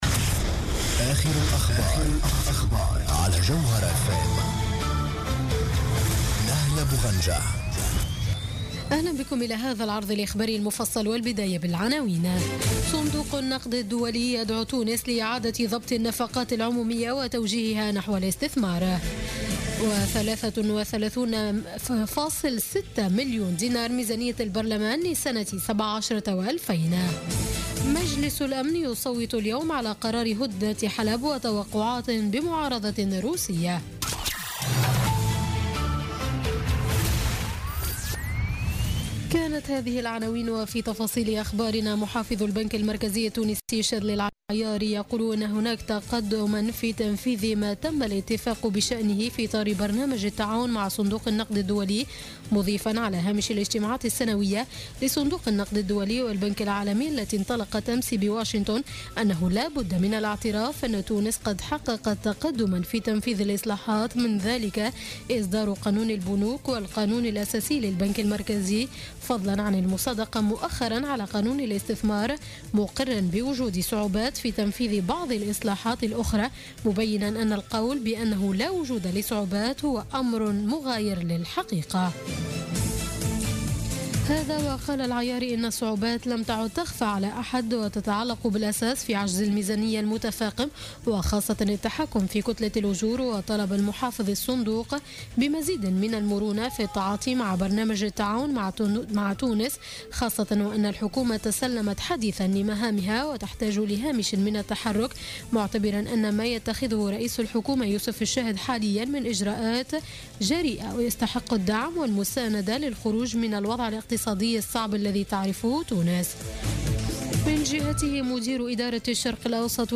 نشرة أخبارمنتصف الليل ليوم السبت 8 أكتوبر 2016